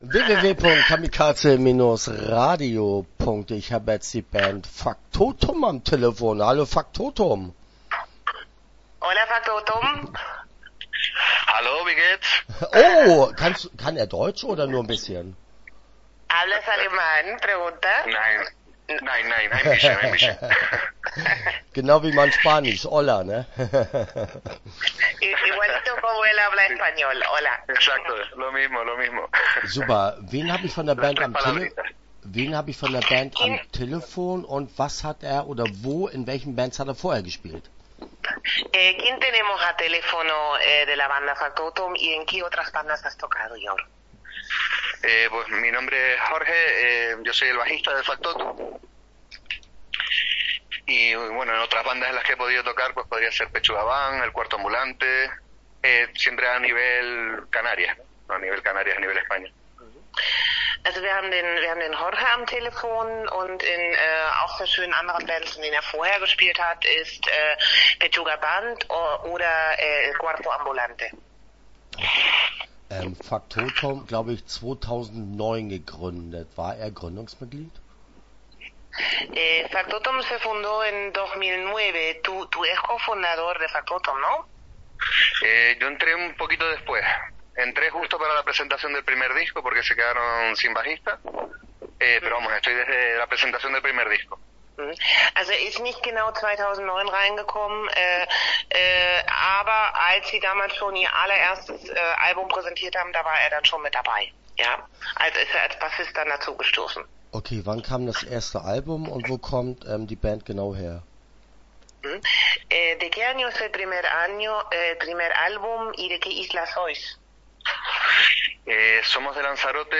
Faktótum - Interview Teil 1 (18:38)